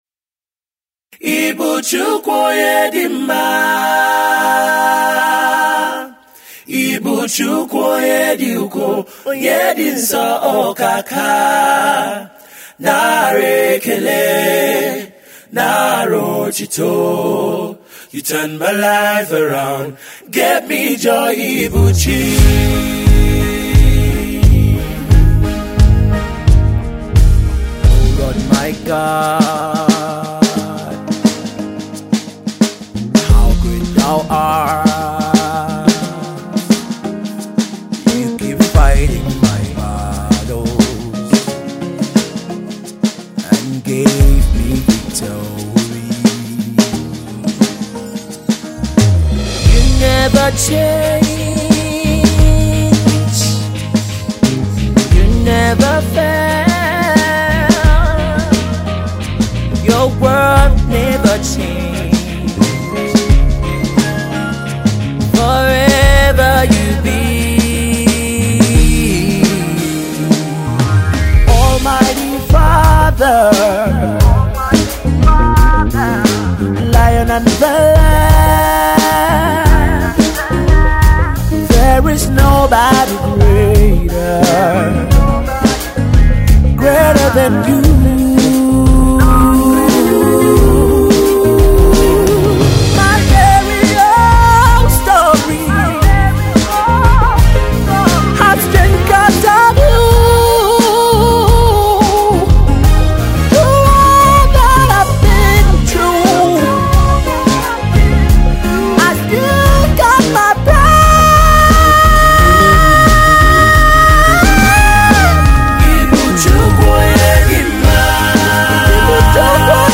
This is their very first recorded studio work, a track